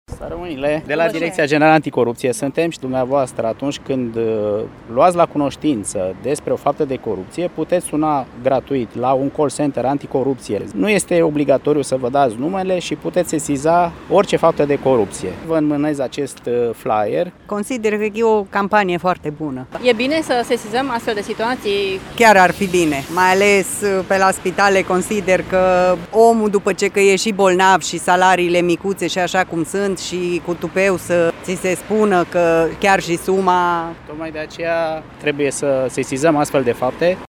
Târgumureșenii cred că e binevenită această campanie și consideră că cele mai multe multe fapte de corupție sunt în spitale: